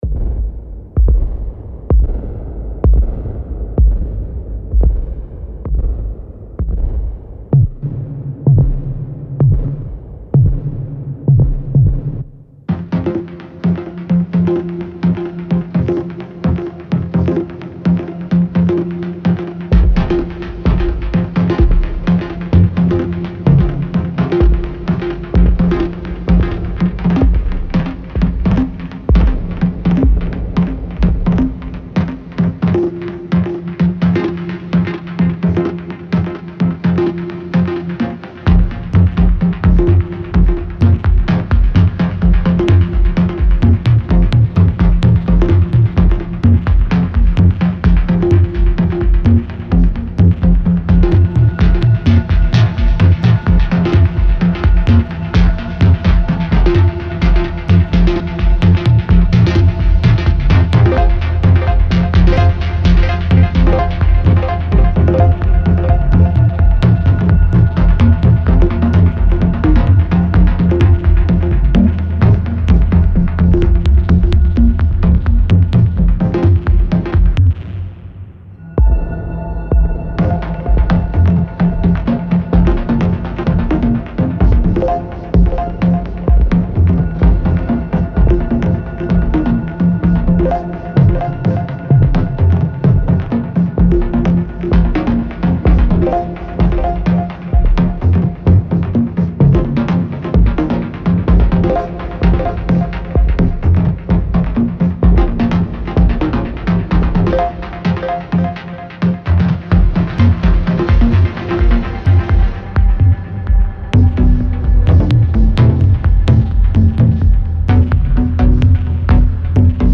Ein innovatives elektronisches Album zum Aufbruch.
Präzise Beats erinnern an Dub Techno.